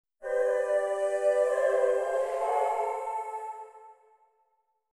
各エフェクトの Mix と、Wet音だけを抽出したものです。
Cakewalk FX Wet
ボーカル・ホール系のプリセットを、ほぼそのまま AUX に置き、リターン量が同じになるように設定。
CW_Fx_wet.mp3